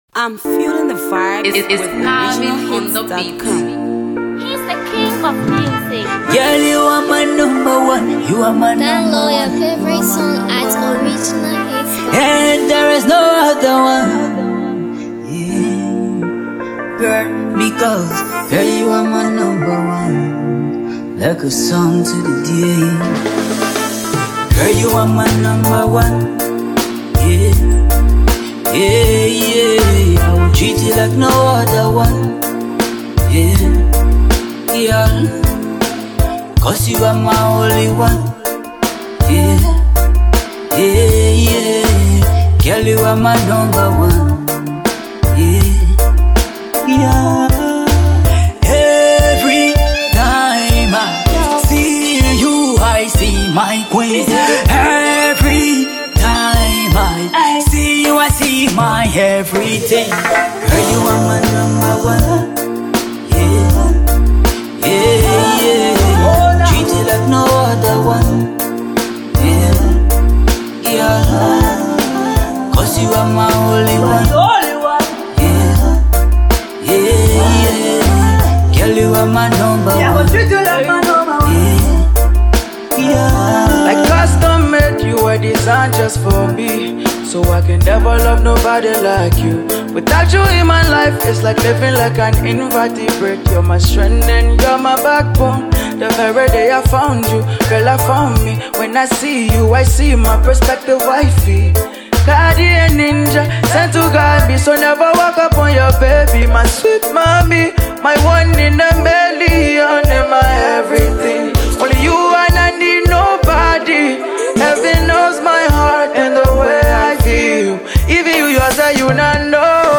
the award-winning Reggae artist
the promising Liberian vocalist